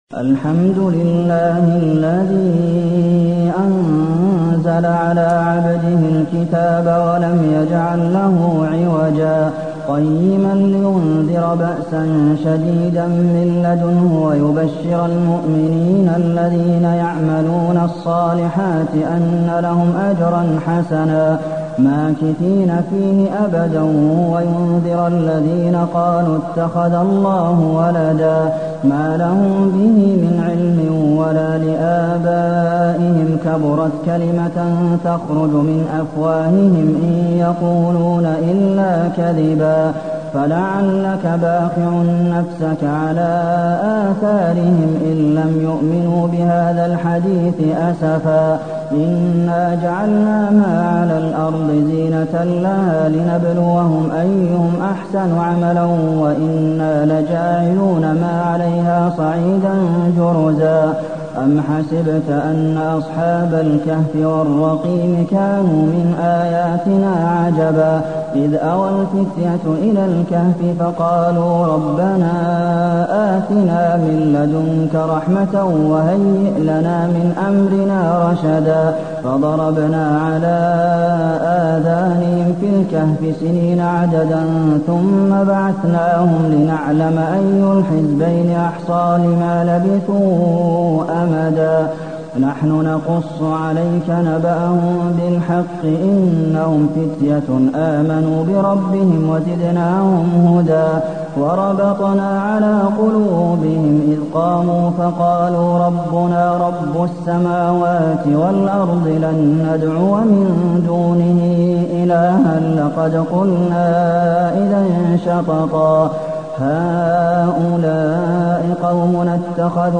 المكان: المسجد النبوي الكهف